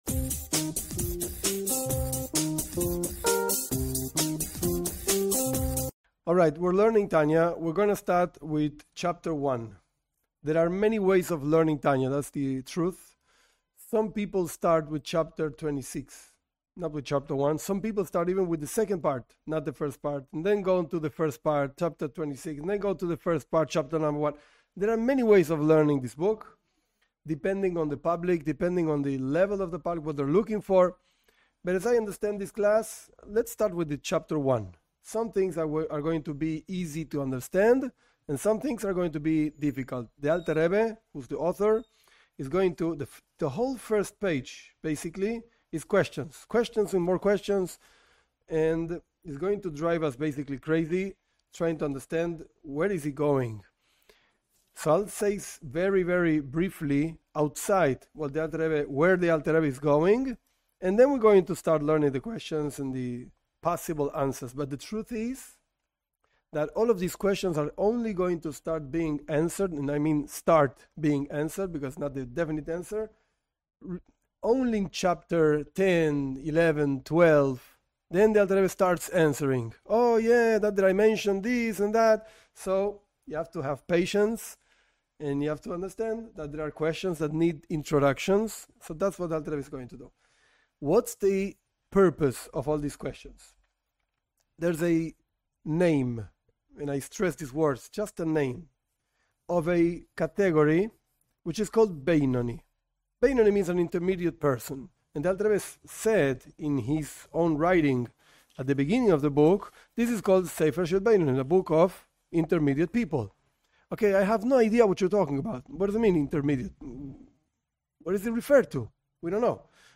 This class teaches Tanya, Book of Beinonim (intermediate people).